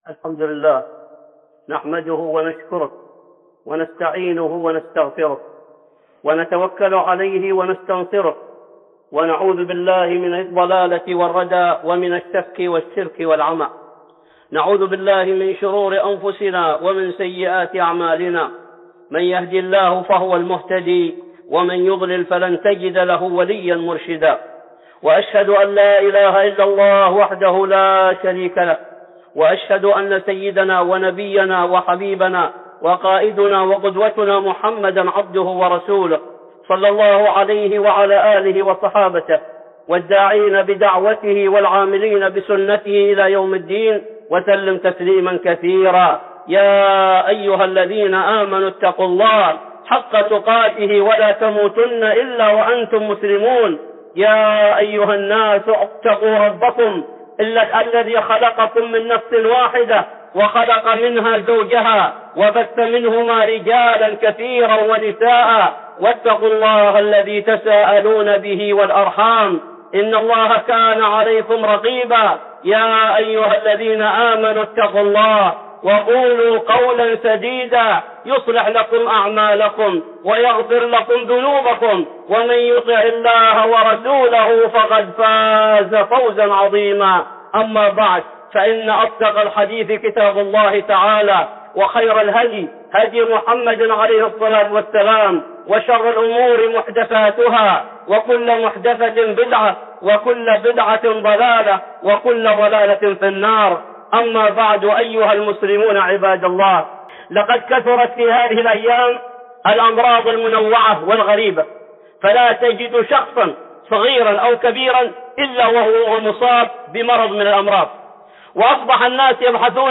(خطبة جمعة) علاج العين والسرطان